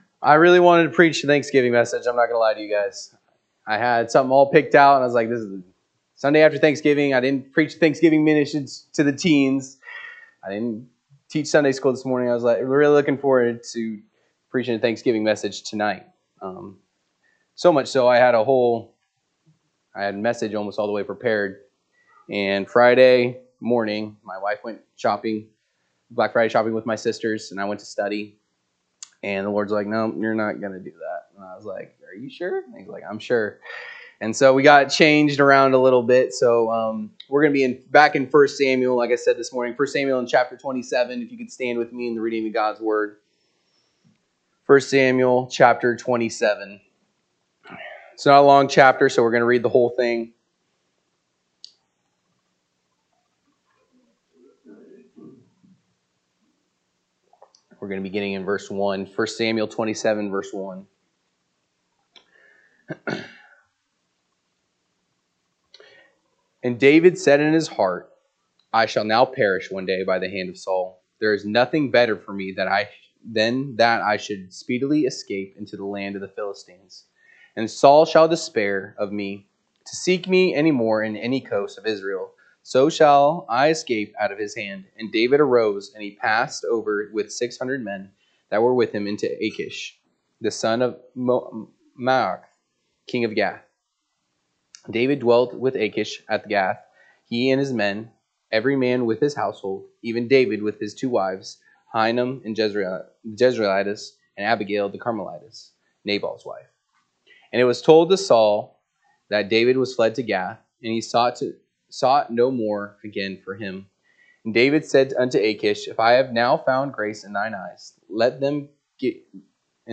November 30, 2025 pm Service 1 Samuel 27 (KJB) 27 And David said in his heart, I shall now perish one day by the hand of Saul: there is nothing better for me than that I should speedily escape…